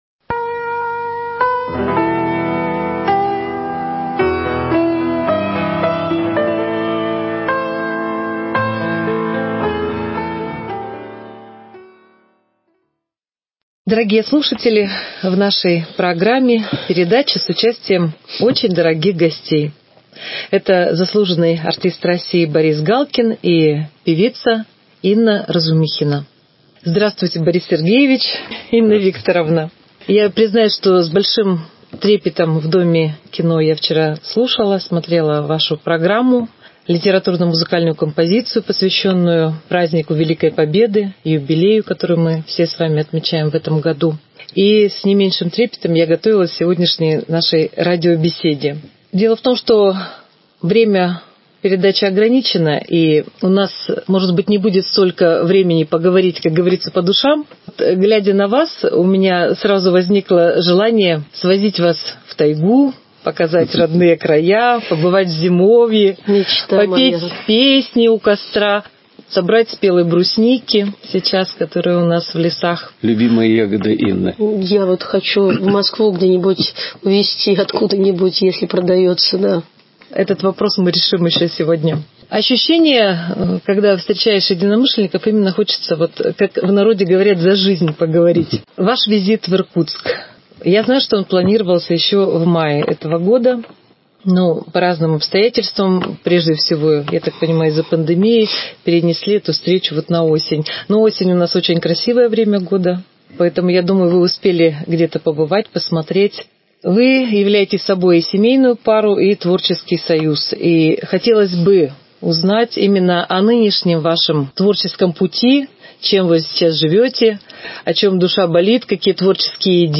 Интервью с московским дуэтом